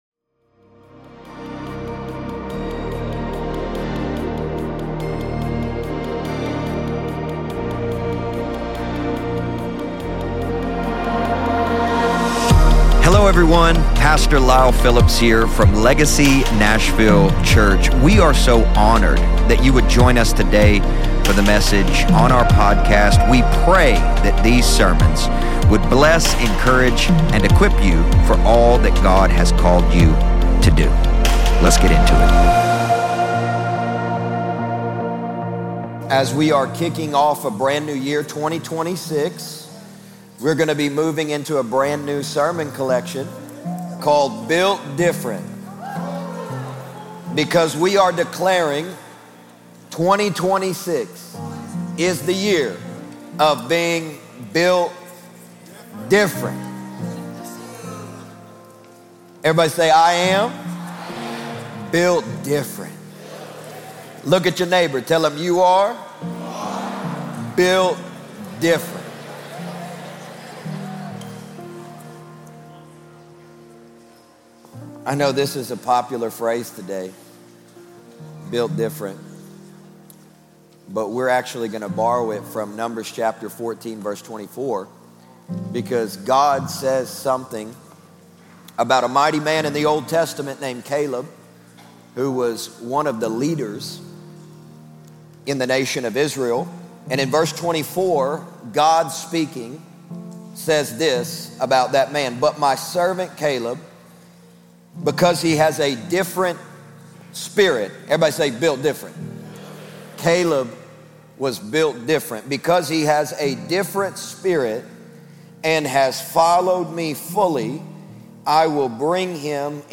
preaches from the subject : Becoming A Believer That Takes Territory